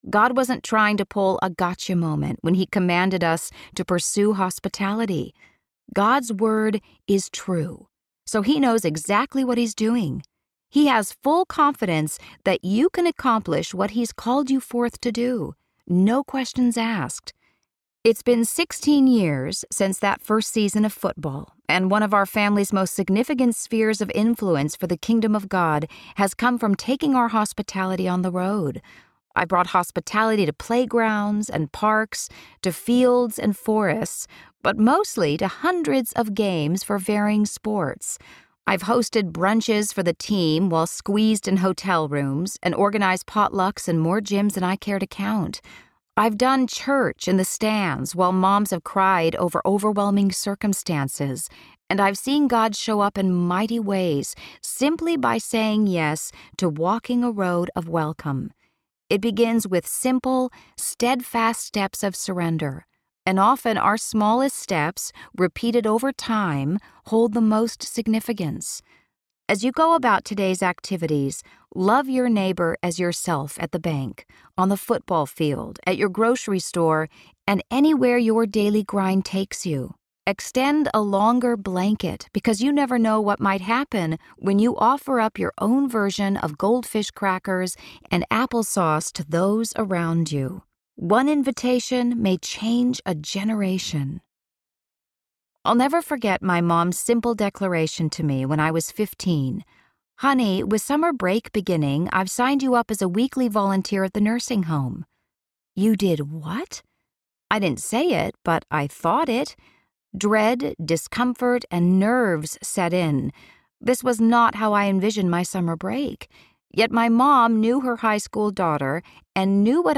Narrator
6.85 Hrs. – Unabridged